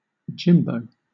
IPA/ˈdʒɪmboʊ/